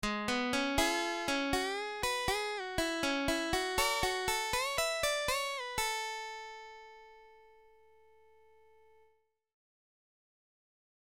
Blues lick > blues 2